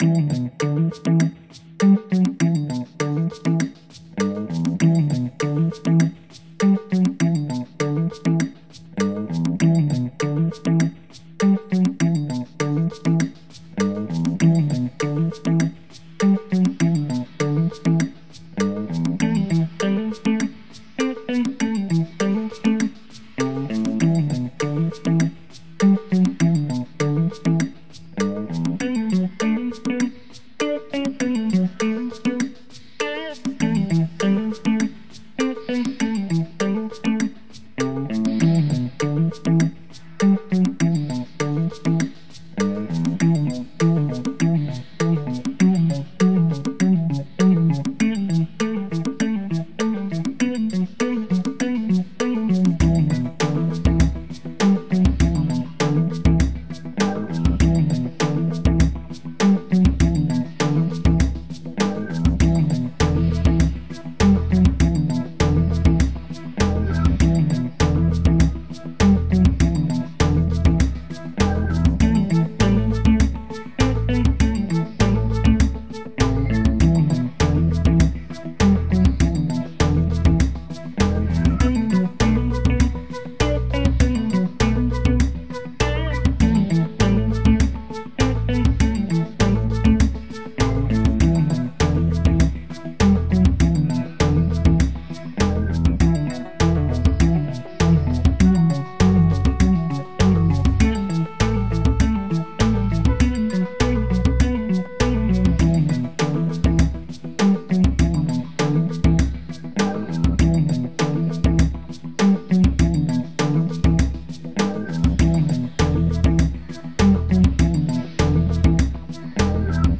Blues a Riff groovy kind